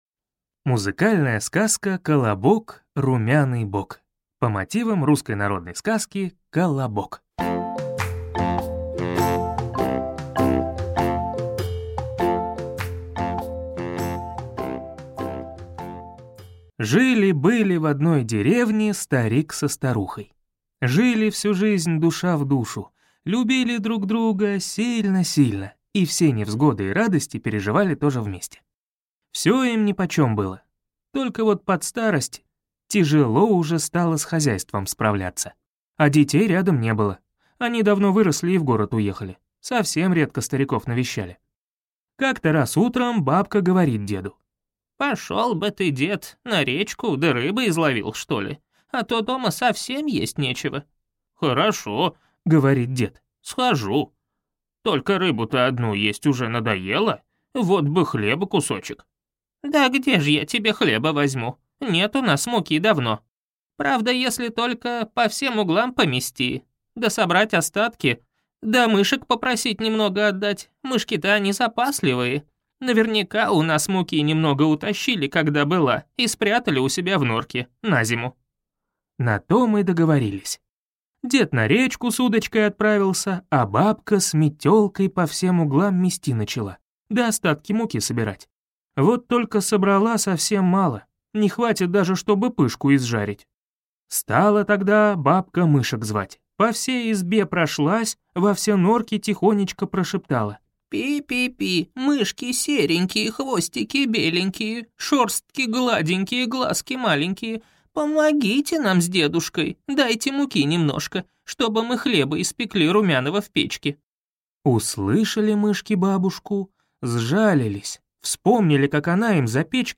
Аудиокнига Колобок – румяный бок | Библиотека аудиокниг